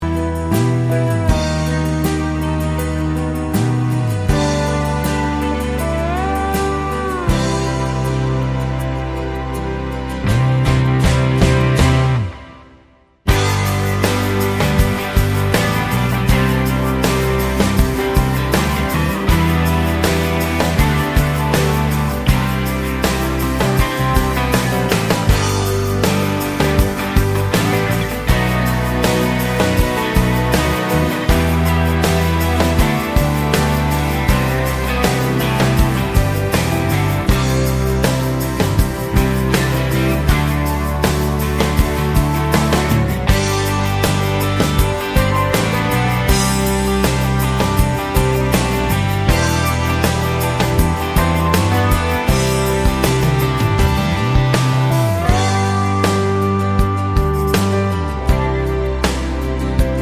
Full Version Country (Male) 3:53 Buy £1.50